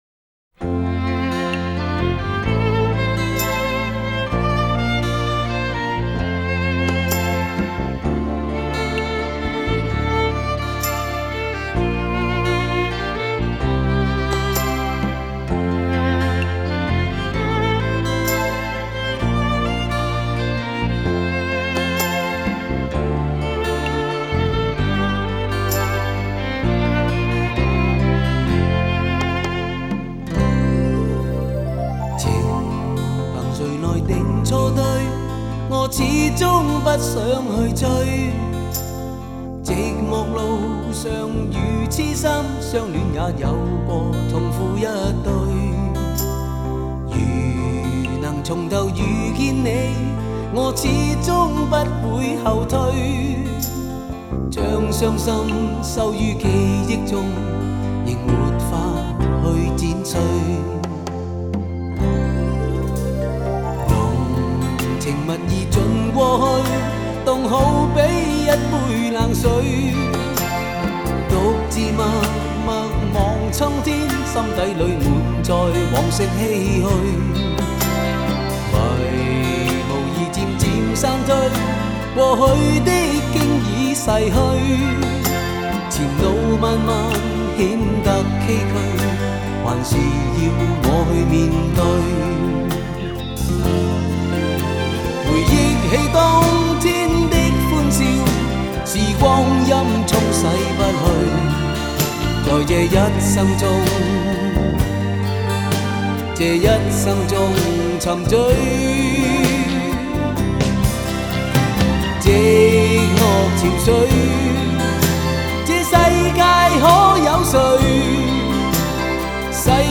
这首歌唱出了爱情的无奈和美好，情感深刻，旋律感人至深。